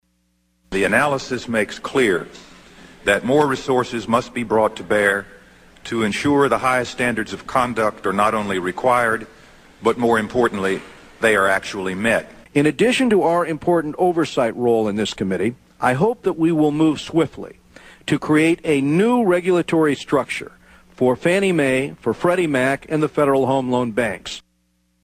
Here is house speaker Nancy Pelosi.